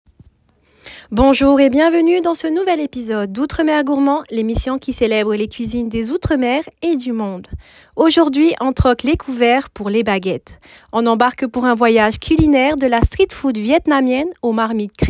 Bande son voix-off